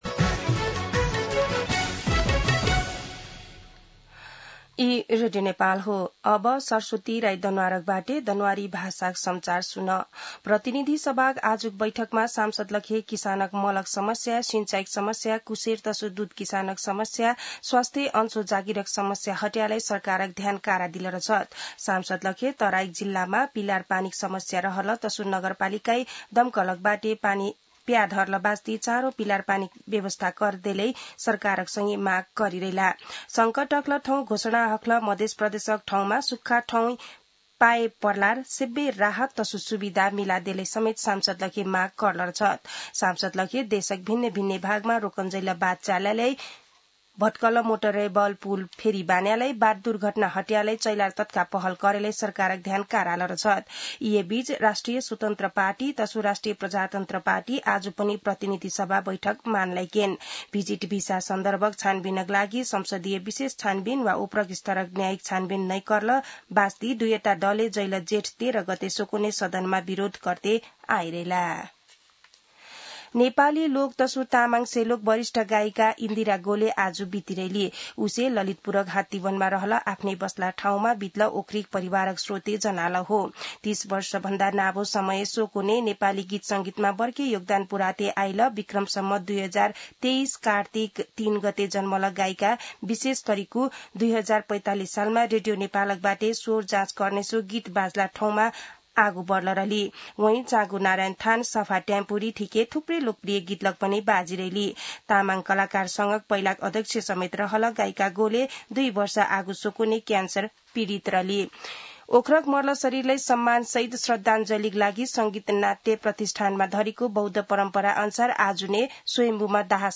दनुवार भाषामा समाचार : १८ भदौ , २०८२
Danuwar-News-5-18.mp3